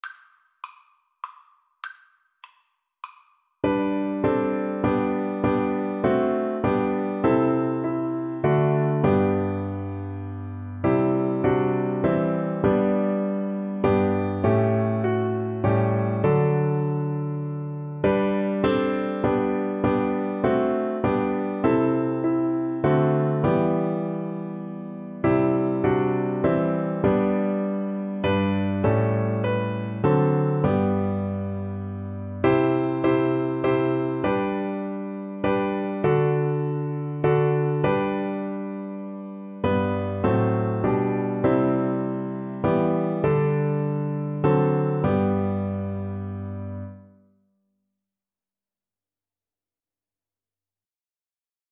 Play (or use space bar on your keyboard) Pause Music Playalong - Piano Accompaniment Playalong Band Accompaniment not yet available transpose reset tempo print settings full screen
Traditional Music of unknown author.
G major (Sounding Pitch) (View more G major Music for Cello )
3/4 (View more 3/4 Music)